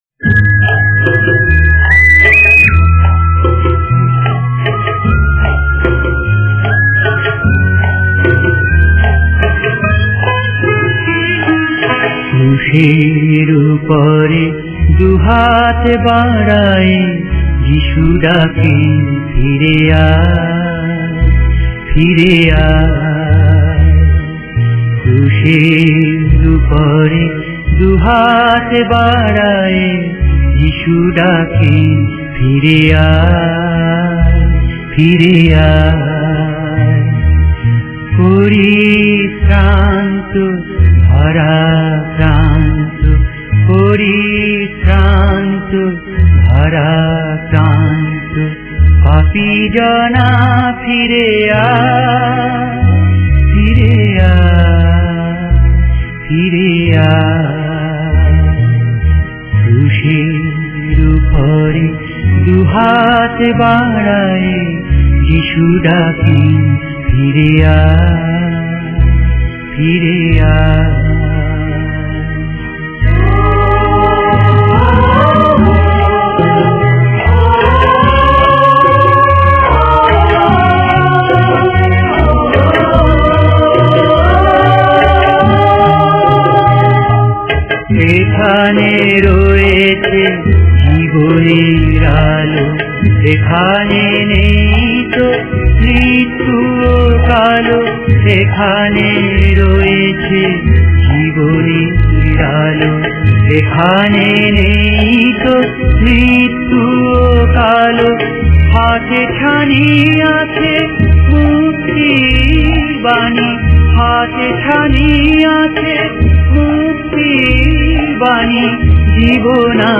Devotional Hymns